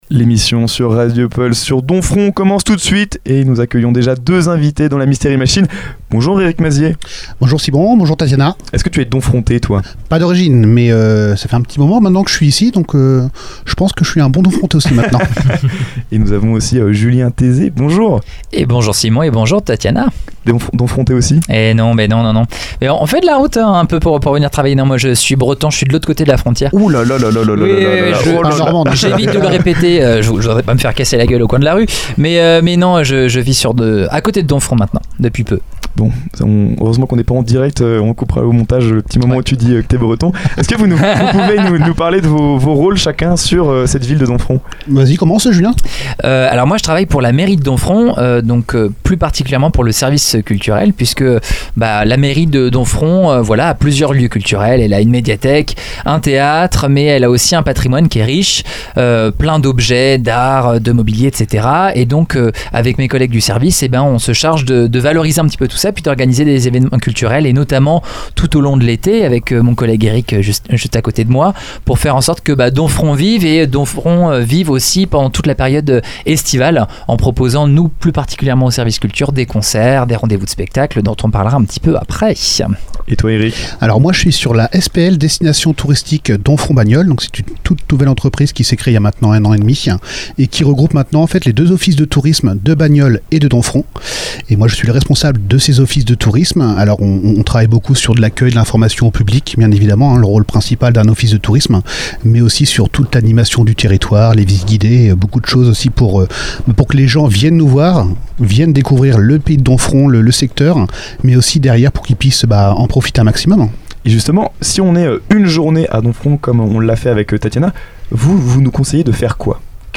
Une interview complémentaire qui met en lumière une destination chargée d’histoire, entre patrimoine, culture et dynamisme estival.